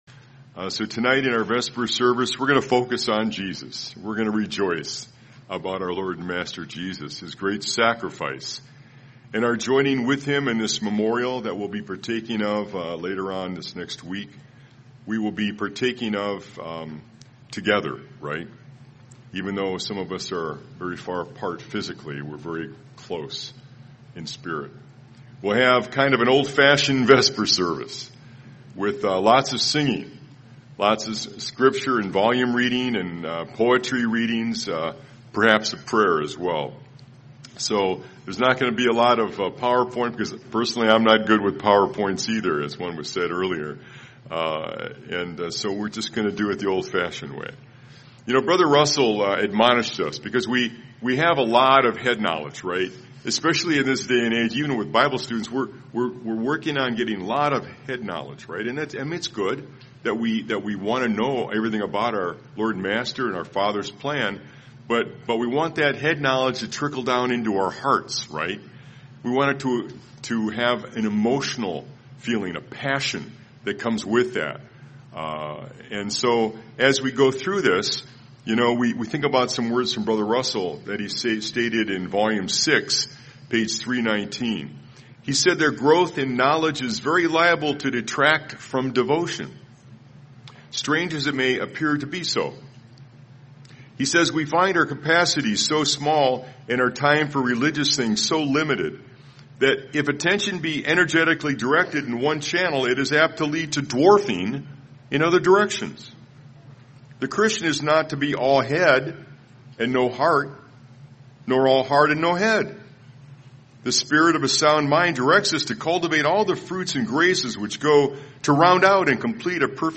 Vesper Service - Bible Christian Resources - Audio, Video, Bible Studies, Christian Mobile Applications
Through scripture readings, hymns, prayers, and poetry, attendees were encouraged to deepen their relationship with Jesus, embrace their trials as tailored for their development, and remain faithful and prepared for the coming kingdom.
Series: 2025 Wilmington Convention